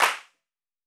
MPC1000_CLAP_TL.wav